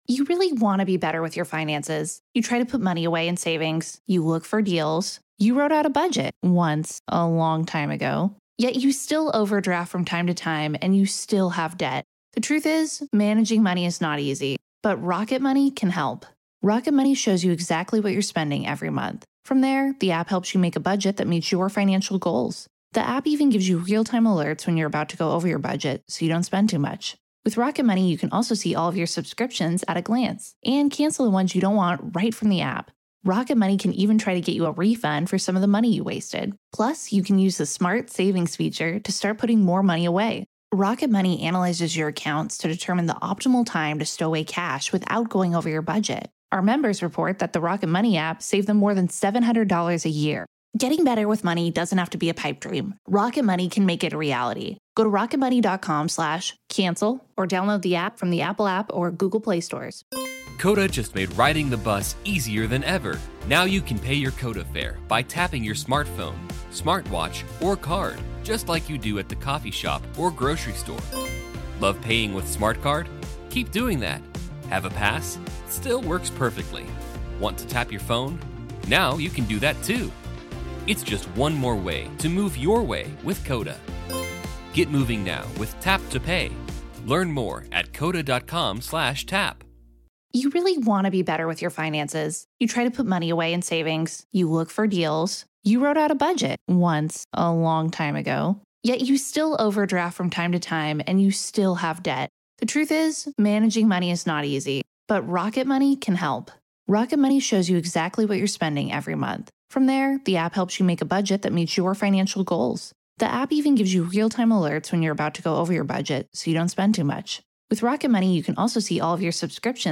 In this episode of The Grave Talks , we explore the journey that transformed a childhood fascination into a serious and often perplexing search for the truth beyond our realm. This is Part Two of our conversation.